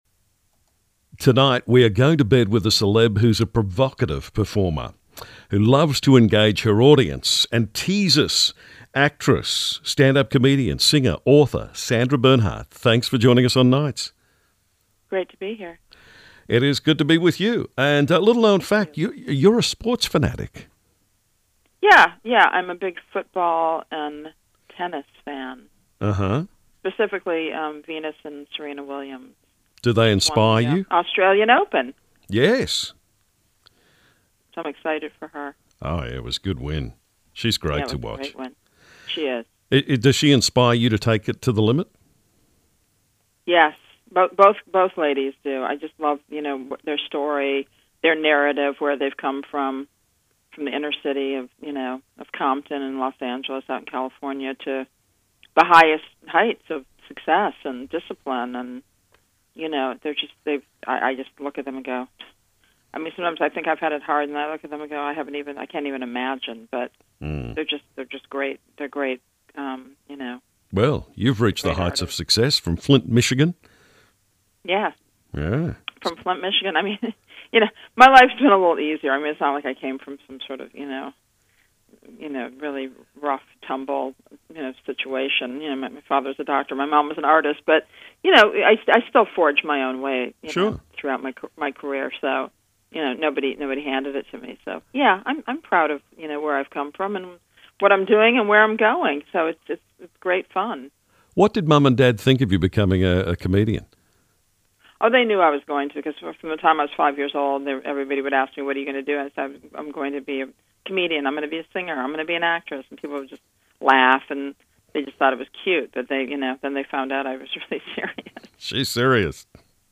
from her home in New York City